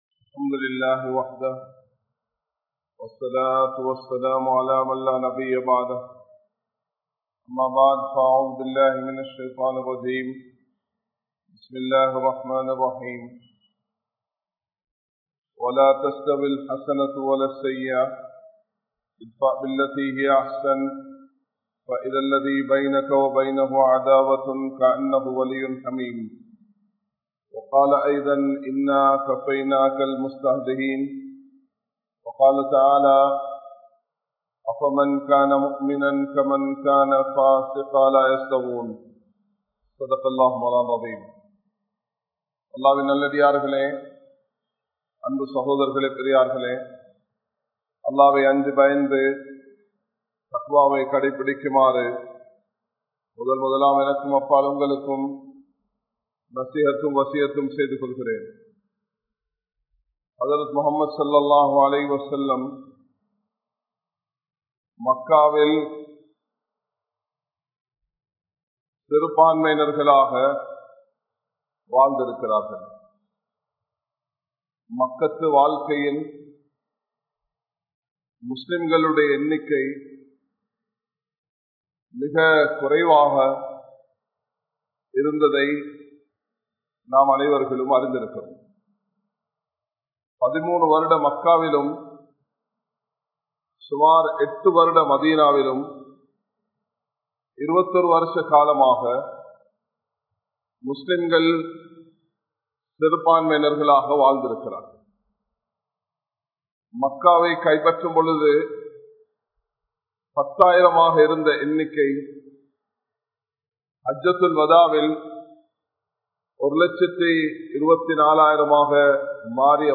Muslimkale! Ondru Padungal | Audio Bayans | All Ceylon Muslim Youth Community | Addalaichenai
Aluthkade, Muhiyadeen Jumua Masjidh